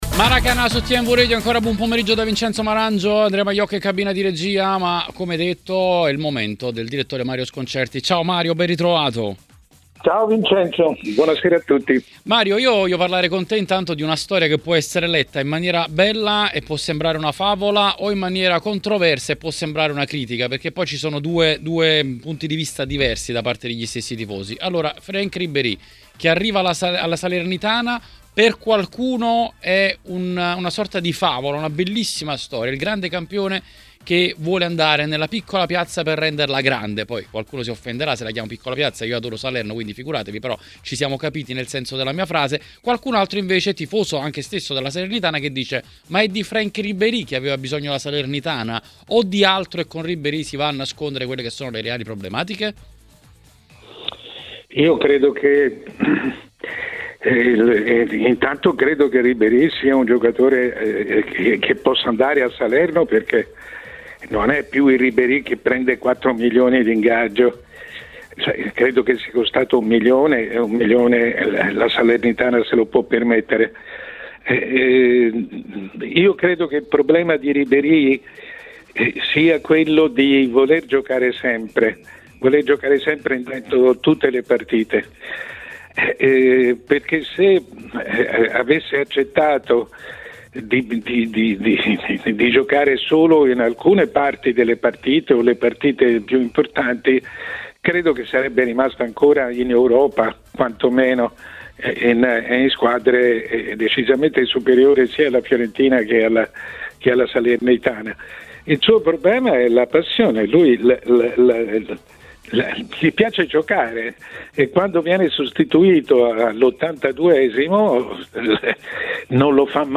Il direttore Mario Sconcerti a Maracanà, nel pomeriggio di TMW Radio, ha detto la sua sui temi di giornata.